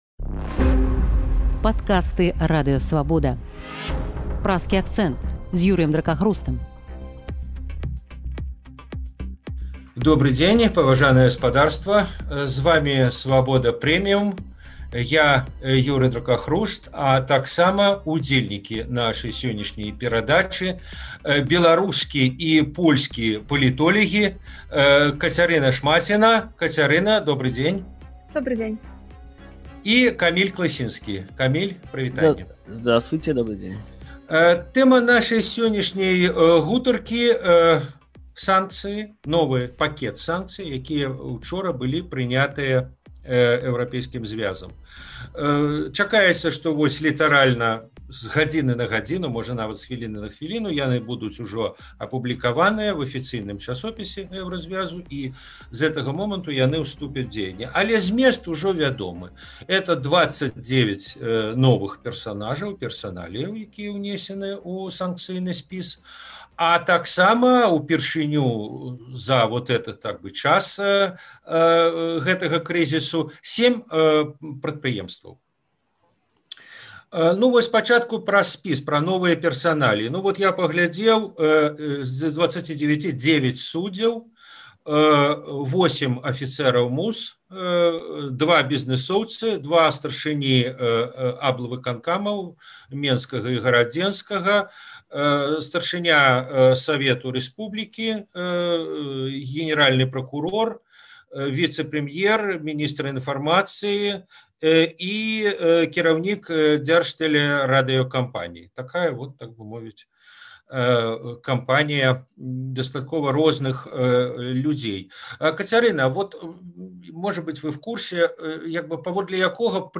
абмяркоўваюць беларускі і польскі палітолягі